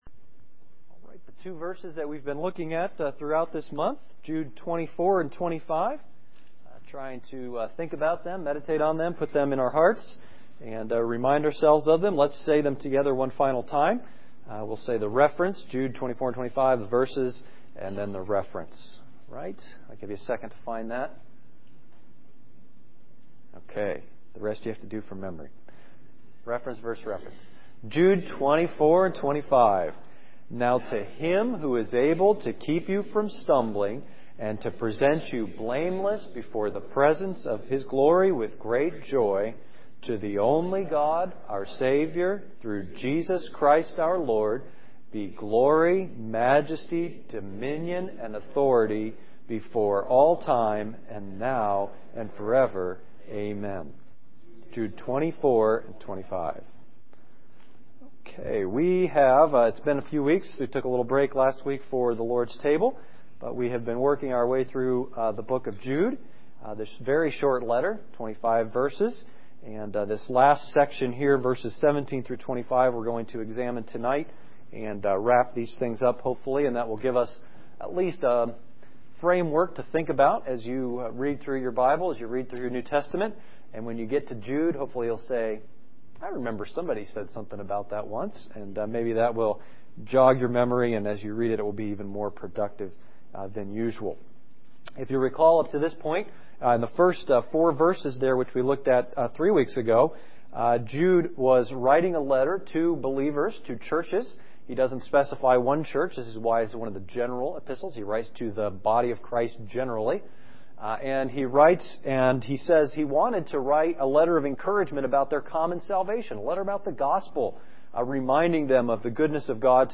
Service Sunday Evening